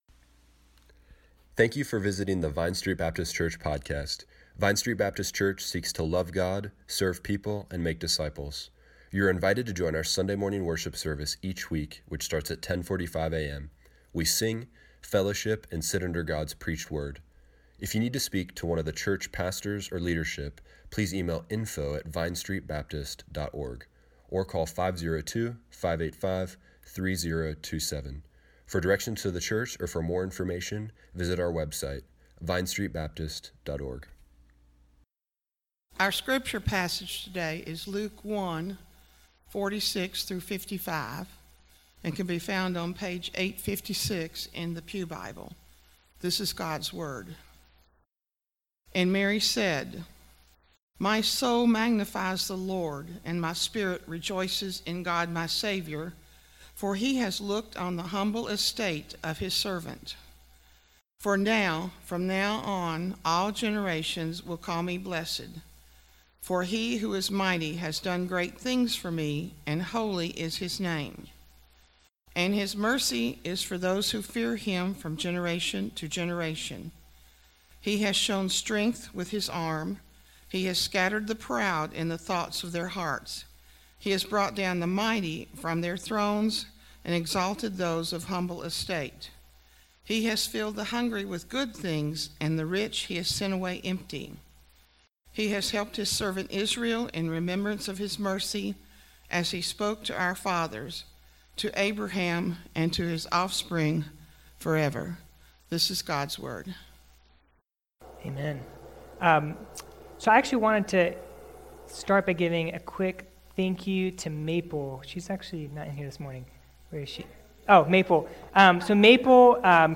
Service Morning Worship Tweet Summary December 1
The First Sunday of Advent Advent is about worship God chooses the weak and humble God chose those who fear him Click here to listen to the sermon online.